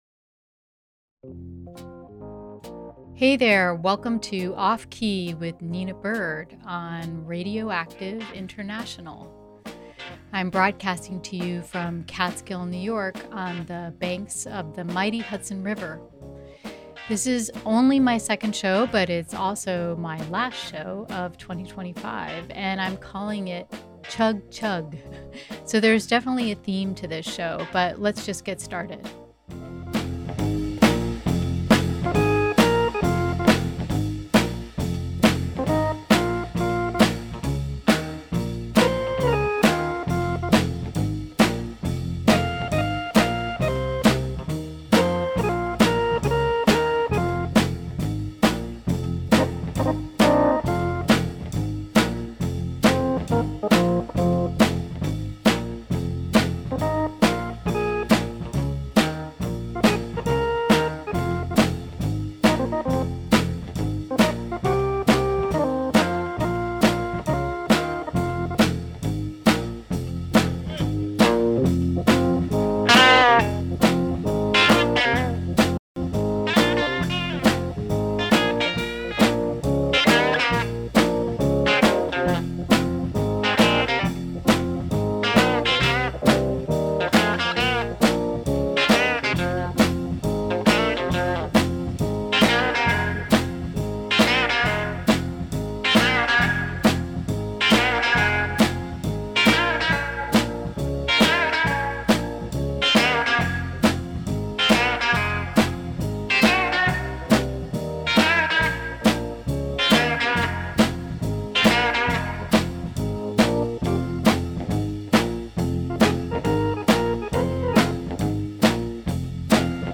A radio show with music, talking, and stories that make not much sense.